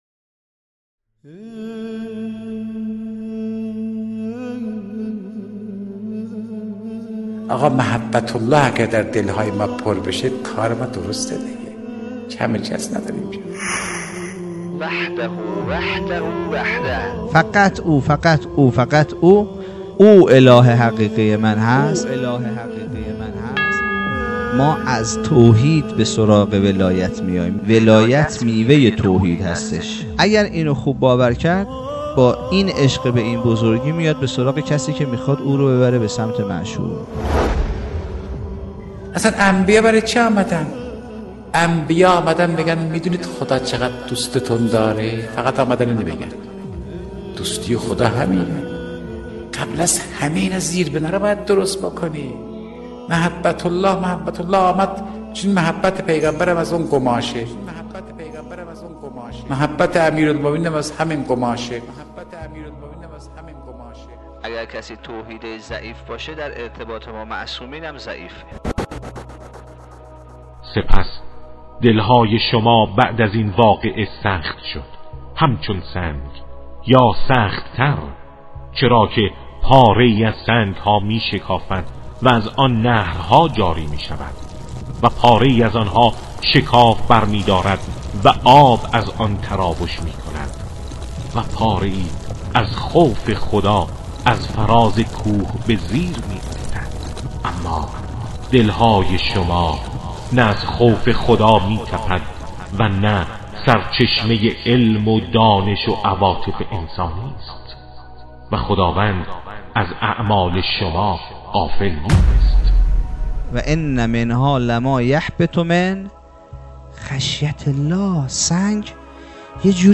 سخنرانی کوتاه